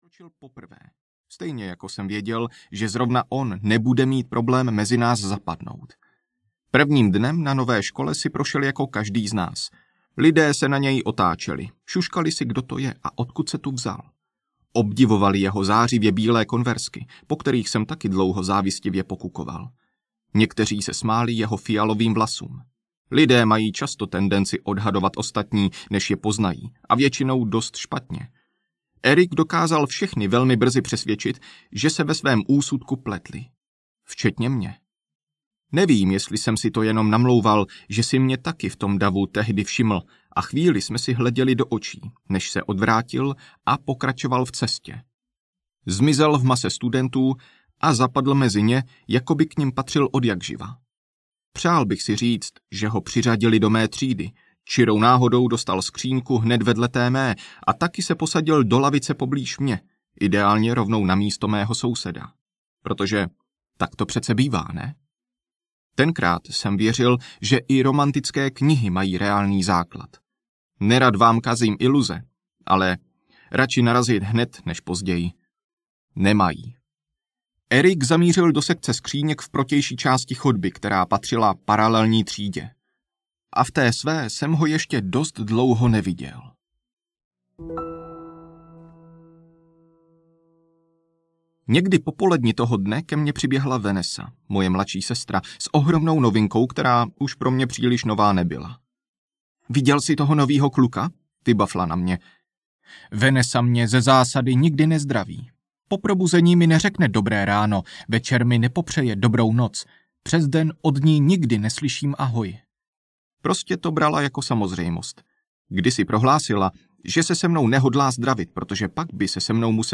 Zlomky nekonečna audiokniha
Ukázka z knihy